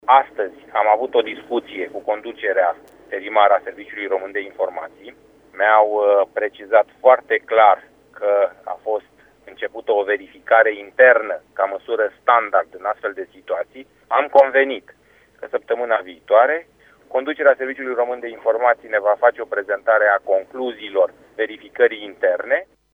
Şeful Comisiei parlamentare, deputatul PSD Georgian Pop: